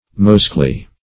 mosaically - definition of mosaically - synonyms, pronunciation, spelling from Free Dictionary Search Result for " mosaically" : The Collaborative International Dictionary of English v.0.48: Mosaically \Mo*sa"ic*al*ly\, adv.